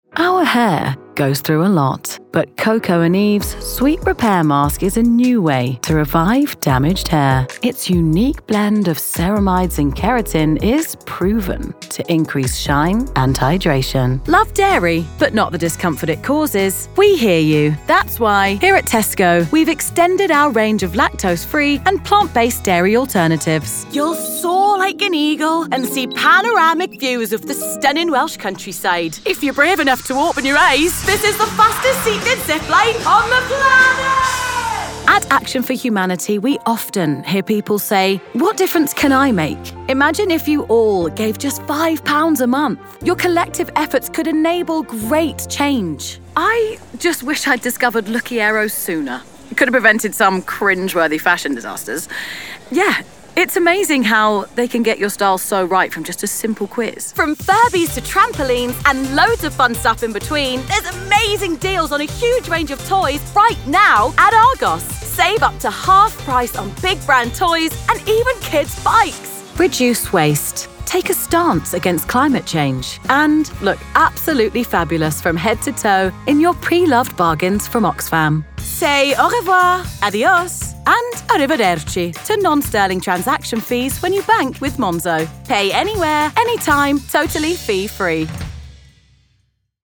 Inglés (Británico)
Versátil, Amable, Natural
Comercial
Her voice is youthful, husky, relatable, and authentic, and her natural accent is a London/Estuary accent.